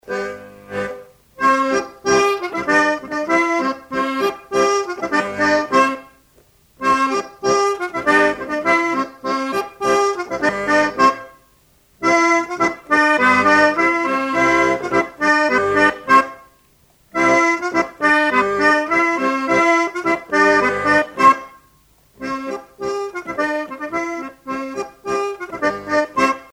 danse-jeu : trompeuse
Pièce musicale éditée